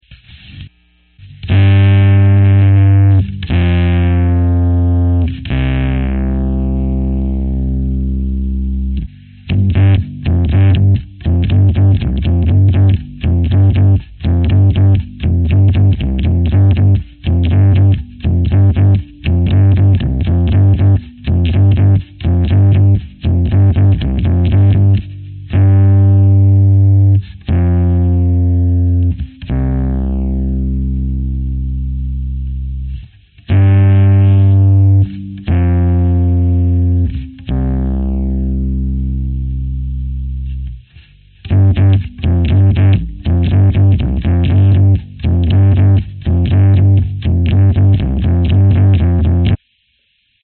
歉意 低音失真
Tag: 低音 器乐 循环 摇滚 C_minor